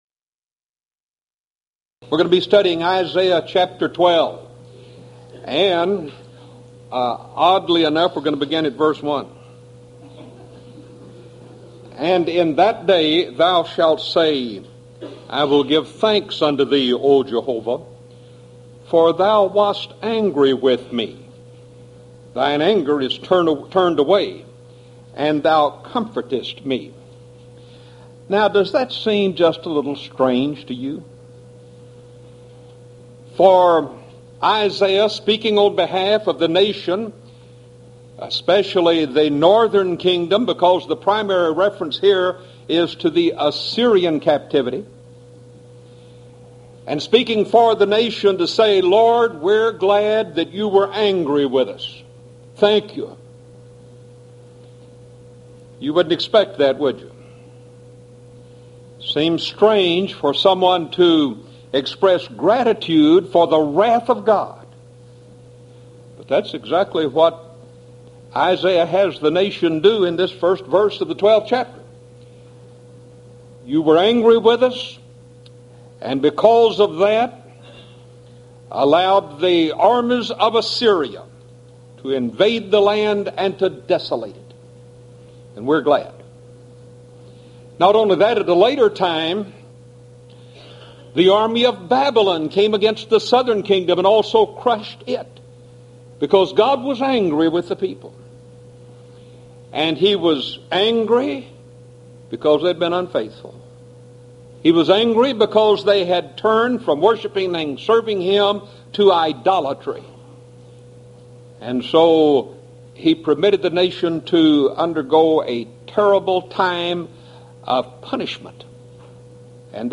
Series: Houston College of the Bible Lectures Event: 1995 HCB Lectures